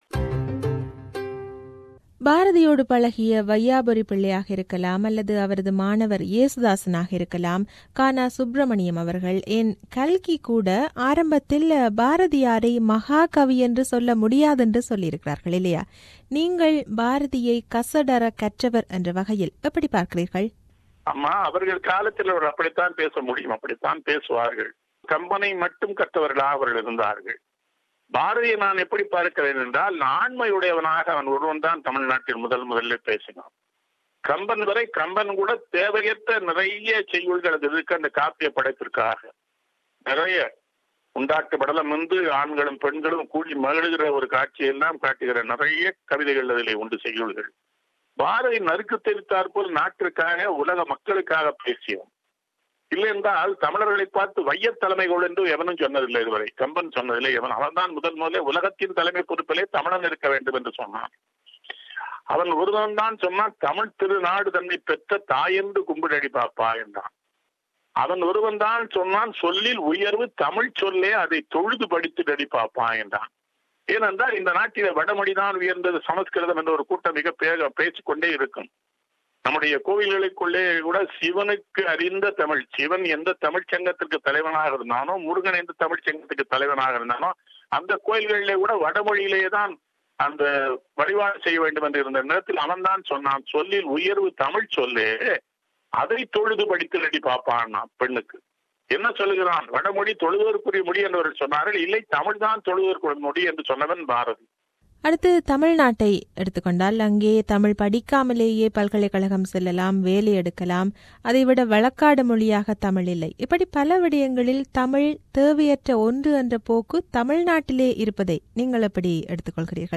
An Interview with Nellai Kannan-P02